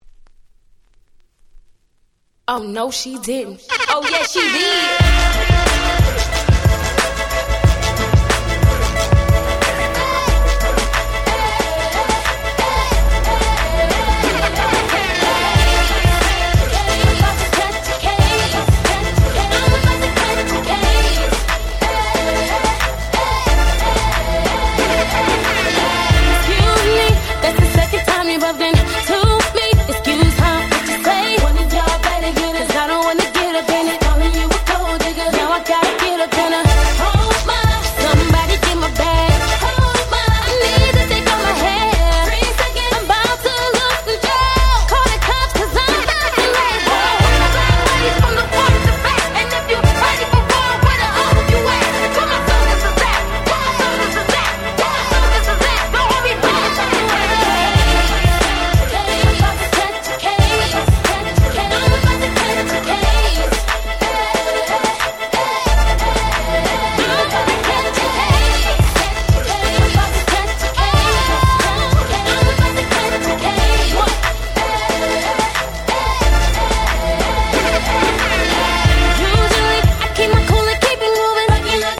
10' Very Nice R&B !!